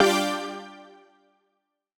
Index of /musicradar/future-rave-samples/Poly Chord Hits/Straight
FR_JPEGG[hit]-G.wav